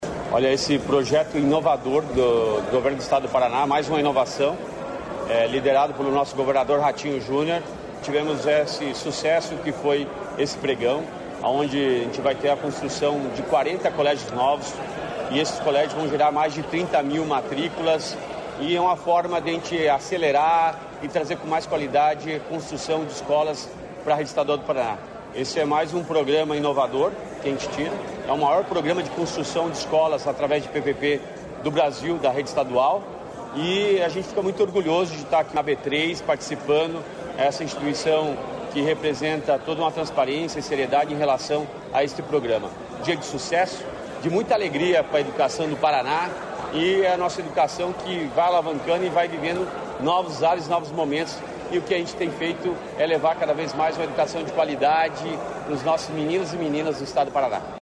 Sonora do secretário da Educação, Roni Miranda, sobre o Leilão da B3